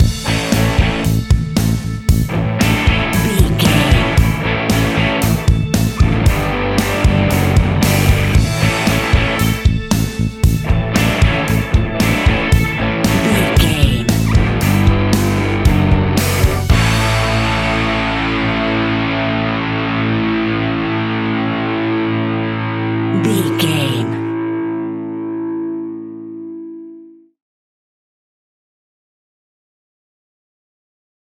Ionian/Major
energetic
driving
heavy
aggressive
electric guitar
bass guitar
drums
hard rock
distortion
instrumentals
distorted guitars
hammond organ